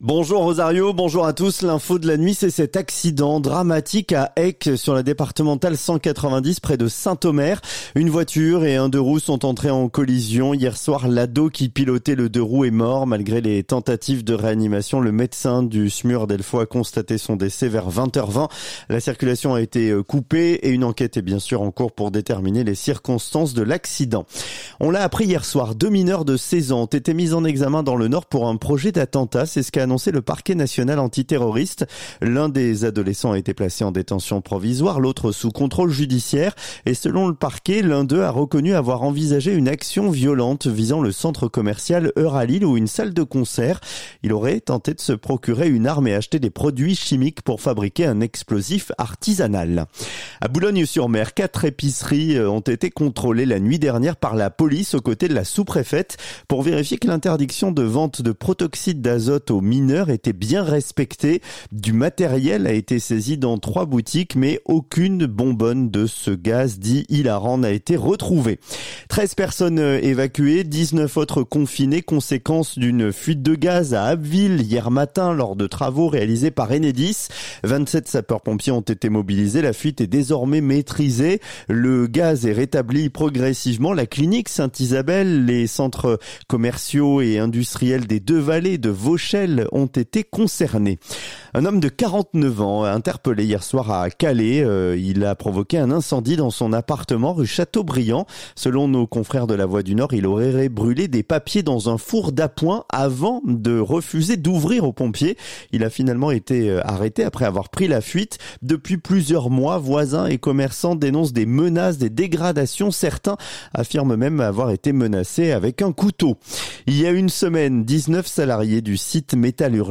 Le journal du samedi 21 février